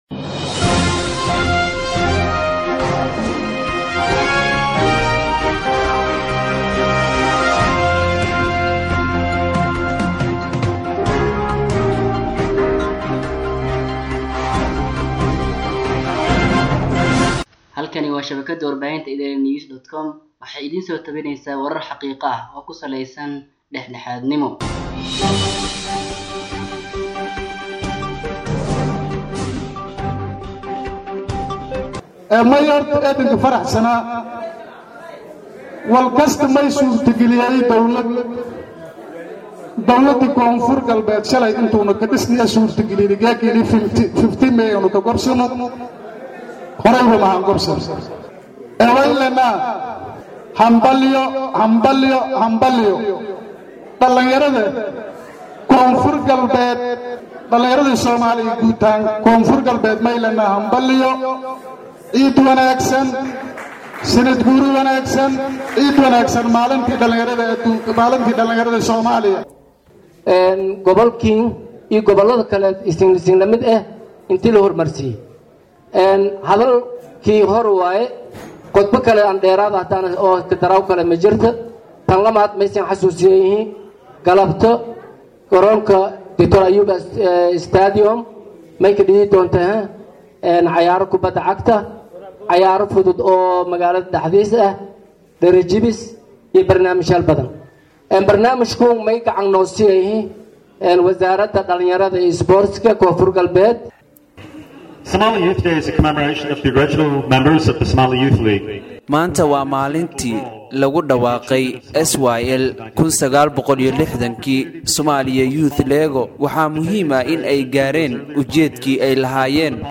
Baydhabo(INO)-Munaasabad si weyn loosoo agaasimay oo lagu maamuusayey maalinta dhalinyarada Soomaaliyeed ee 15ka May ayaa maanta lagu qabtay magaalada  Baydhabo ee  Xarunta Gobolka Bay.